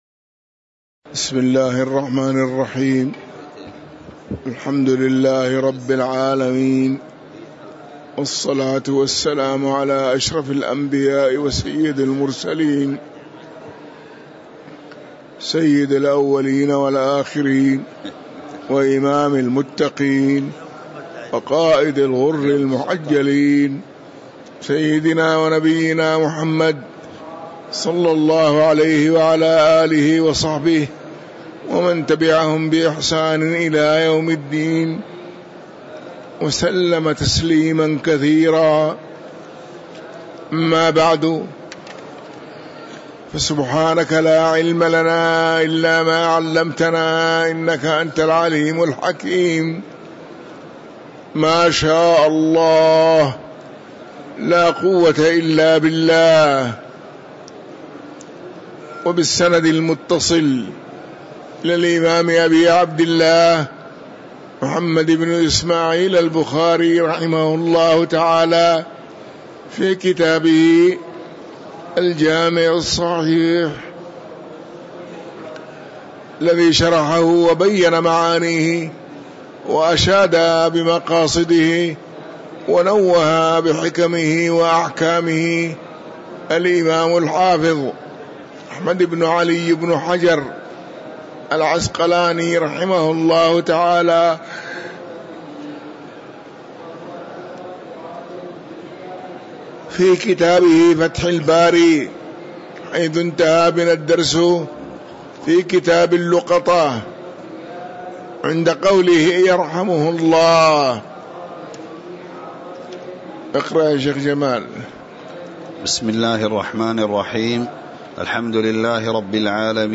تاريخ النشر ١٩ رجب ١٤٤٥ هـ المكان: المسجد النبوي الشيخ